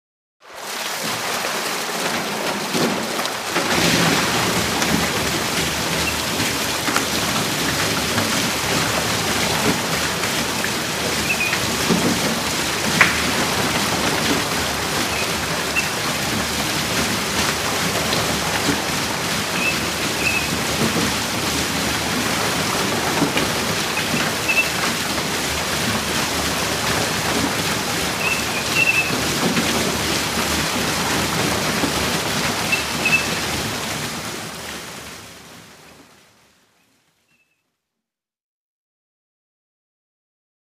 Water Wheel; Water Wheel; Water Spilling / Wooden Clunks / Occasional Squeaks, Close Perspective.